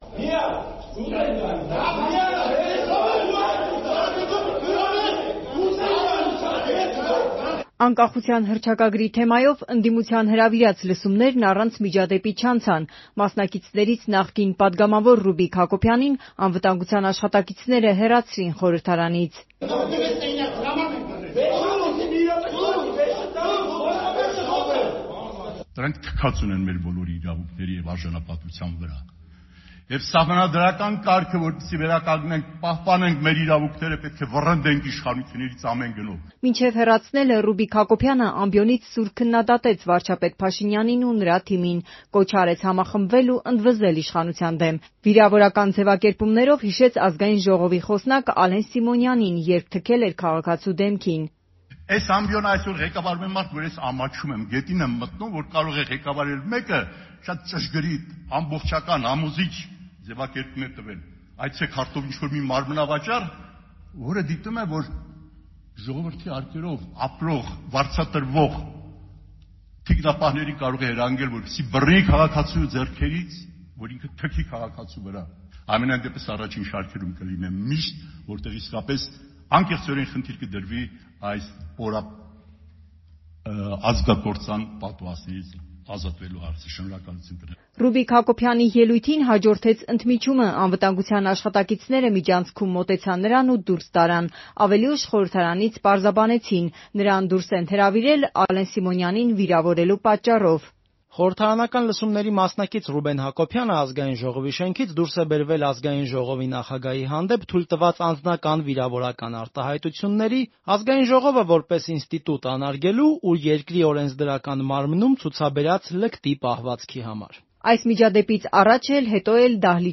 Ռեպորտաժներ
Միջադեպ խորհրդարանում՝ Անկախության հռչակագրի թեմայով լսումների ընթացքում